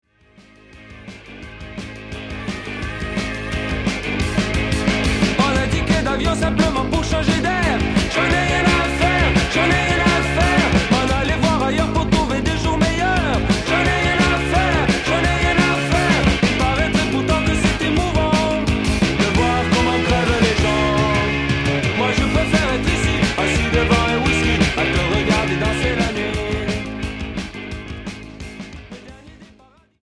Rock Mod Unique 45t retour à l'accueil